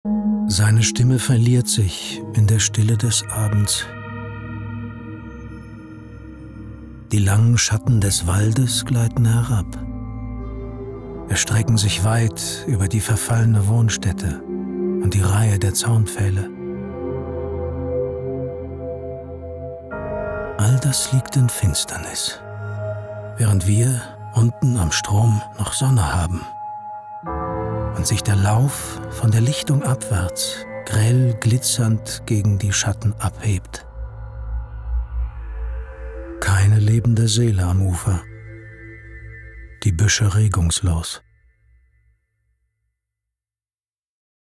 markant, dunkel, sonor, souverän
Mittel plus (35-65)
Commercial (Werbung)